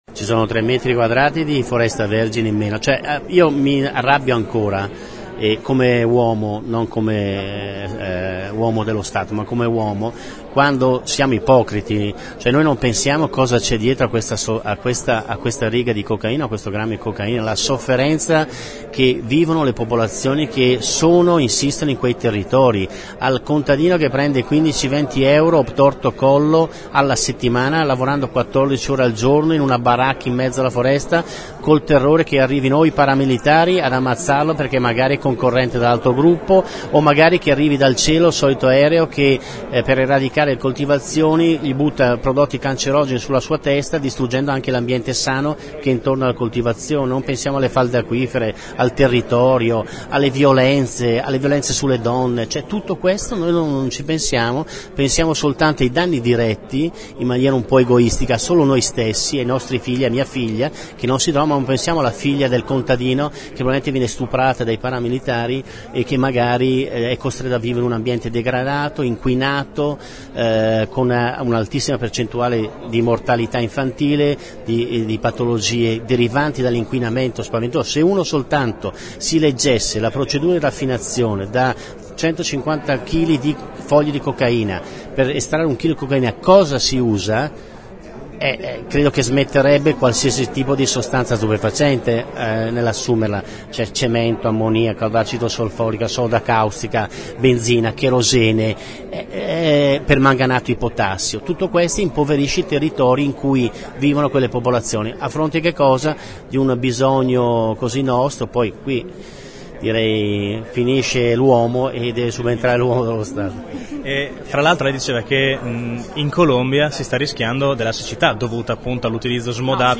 Davanti ad un pubblico di circa 200 studenti provenienti da alcune dell scuole superiori della città coinvolte nel progetto, il capo della squadra mobile ha voluto far riflettere i ragazzi su cosa ci sia dietro ad ogni grammo di cocaina.